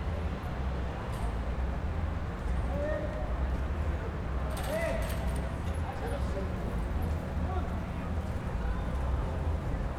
UrbanSounds
Environmental
Streetsounds